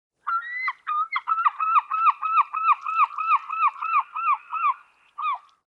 「日本の鳥百科」セグロカモメの紹介です（鳴き声あり）。翼を広げると150㎝にもなる大形のカモメの仲間。